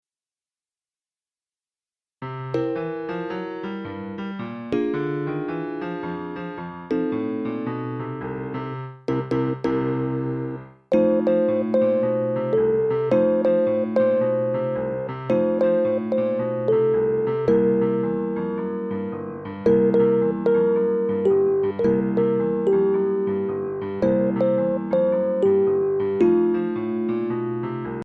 Mp3 Instrumental Track with Melody for easy learning